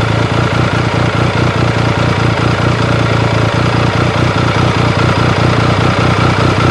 Motor 1.wav